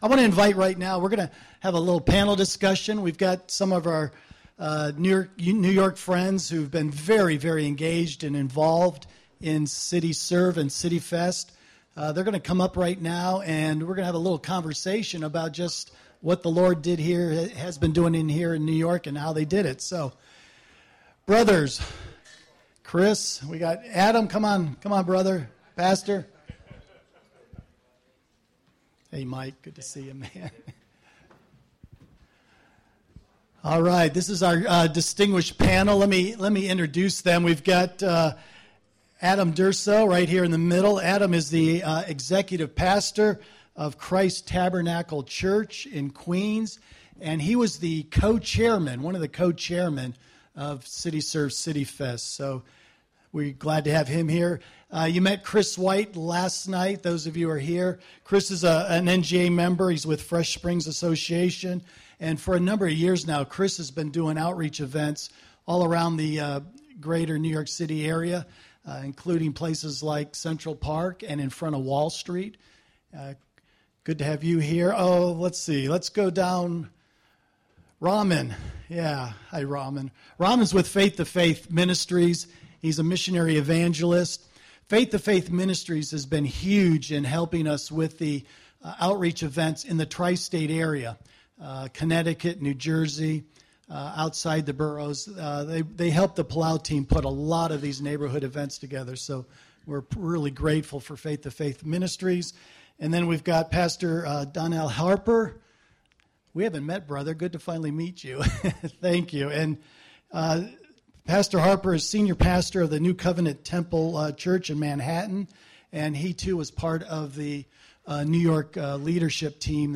Panel Discussion
Church-Mobilization-Panel-Discussion-7.31.15.mp3